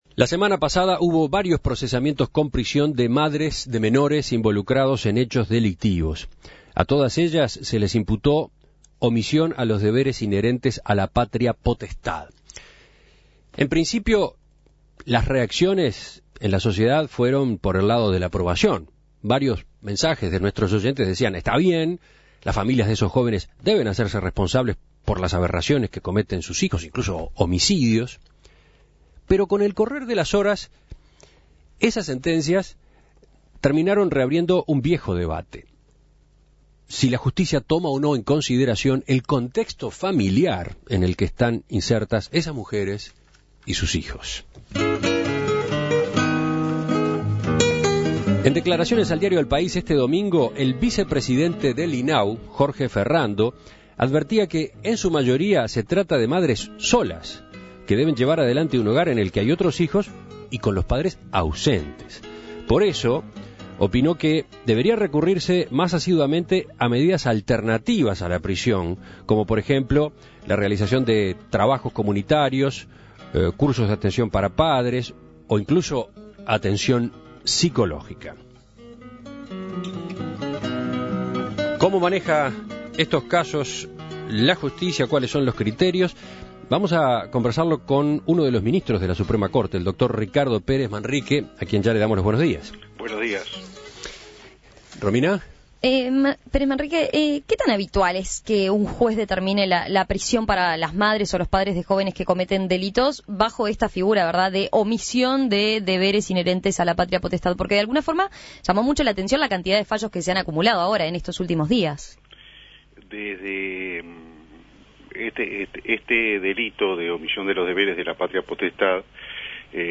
Escuche la entrevista a Ricardo Pérez Manrique